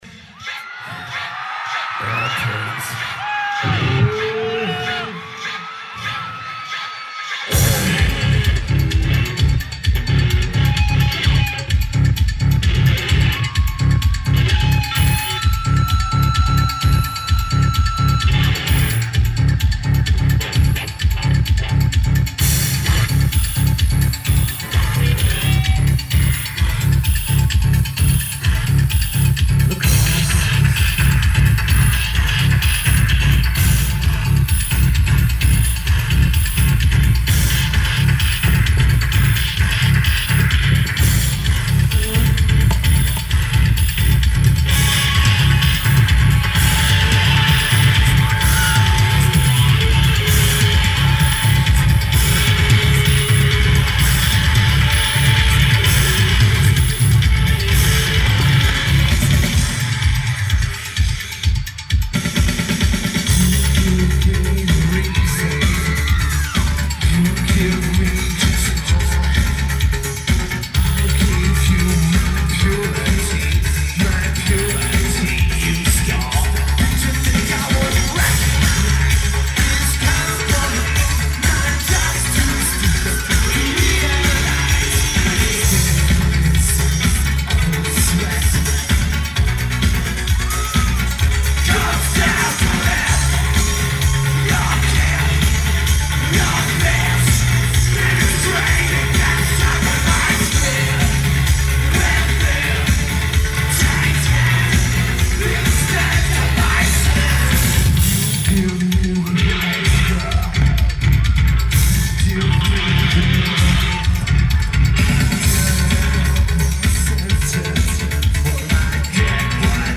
LaCrosse Center
Lineage: Audio - AUD (Sonic Studios DSM6 + Sony TCD-D7)